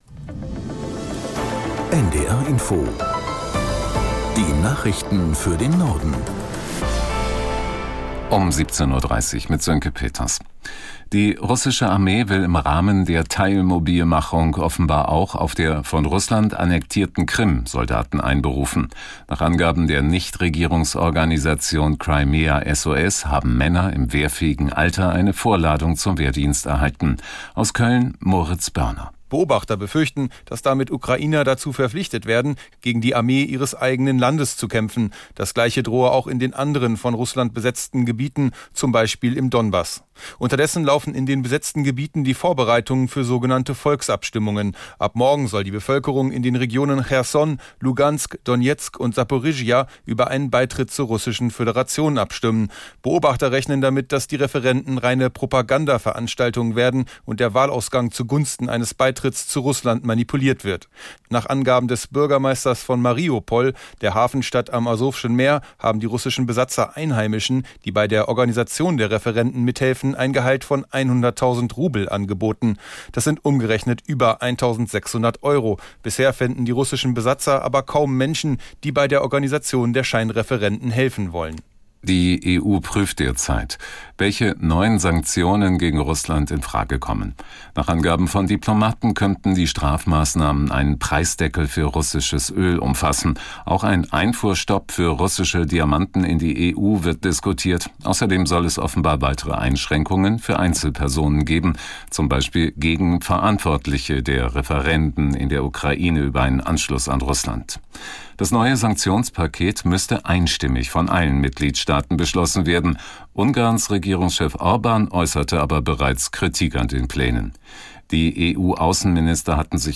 Nachrichten - 22.09.2022